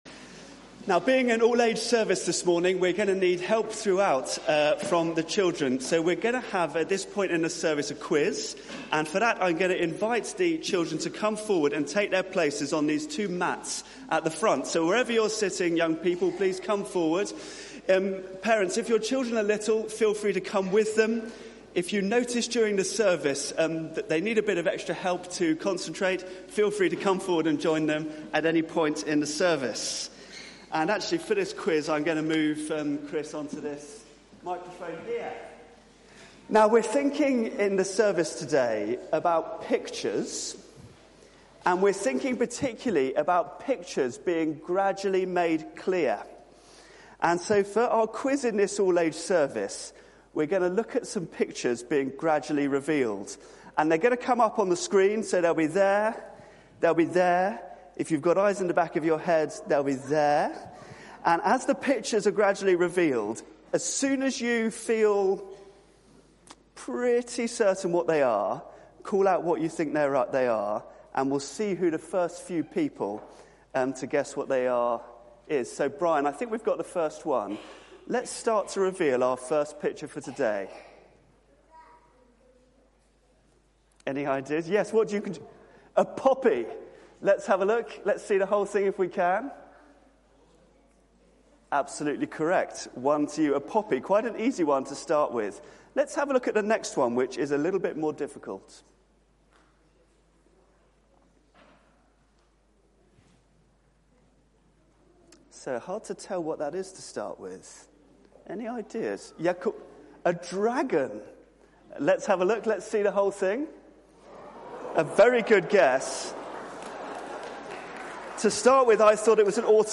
Media for 9:15am Service on Sun 10th Nov 2019 09:15 Speaker
Theme: Why do bad things happen? Sermon Search the media library There are recordings here going back several years.